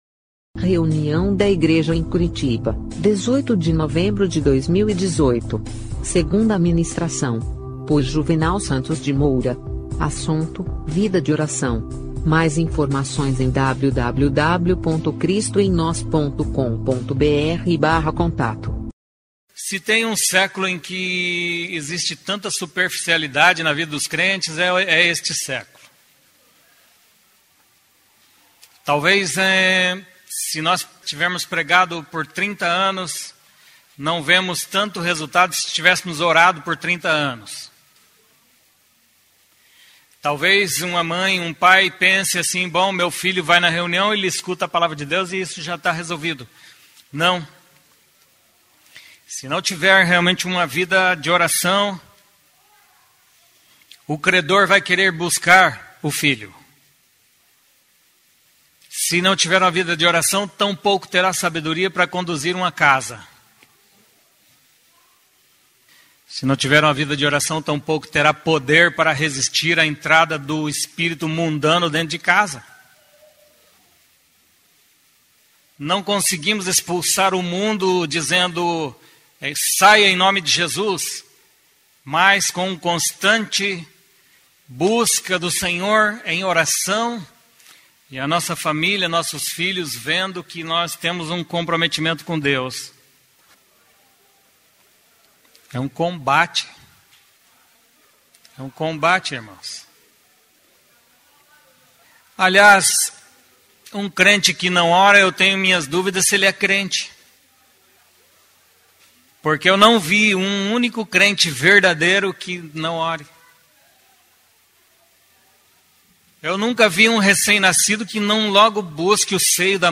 Segunda mensagem
da reunião da igreja em Curitiba no dia 18/11/2018.